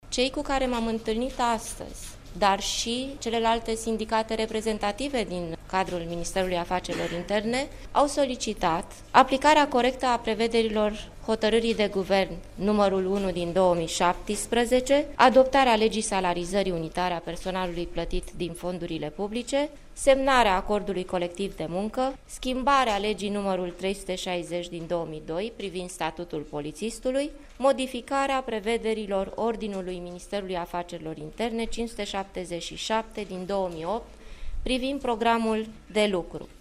Ea a explicat că, în urma discuţiilor pe care le-a avut în ultimele zile cu sindicatele din MAI, au fost depuse mai multe amendamente în acest sens: